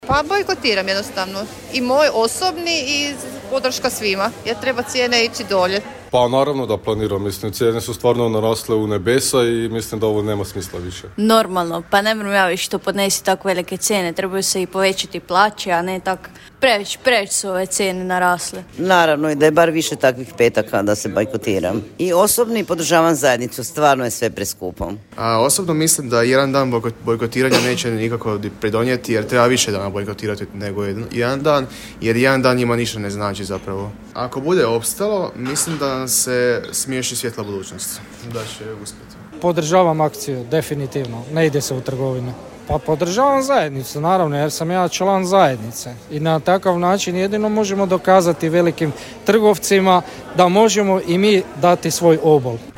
ANKETA Pitali smo vas hoćete li sutra podržati bojkot trgovina, evo što ste nam rekli - Podravski radio | 87,6 MHz